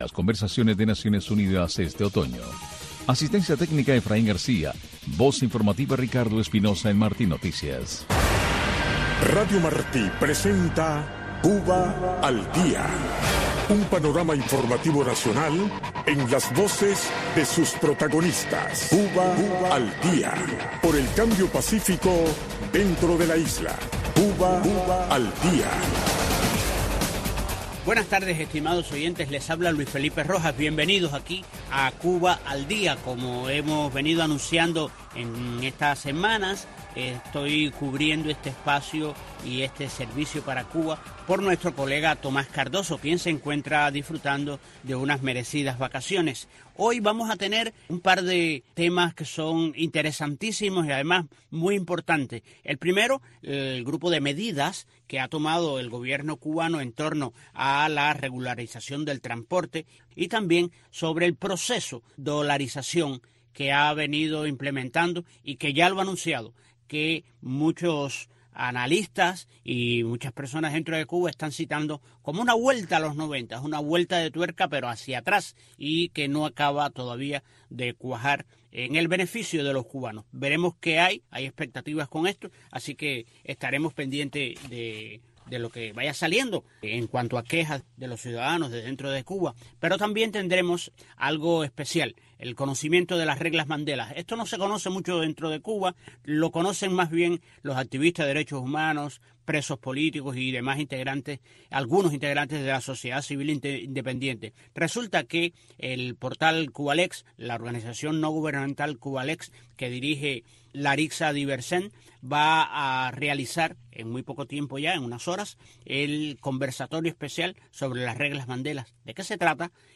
espacio informativo en vivo